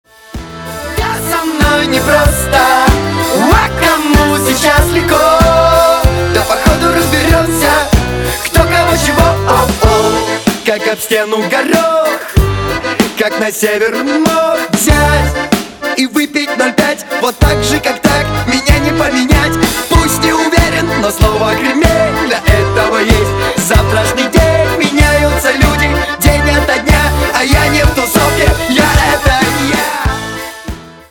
• Качество: 320, Stereo
громкие
инструментальные
гармонь
Зажигательный рингтон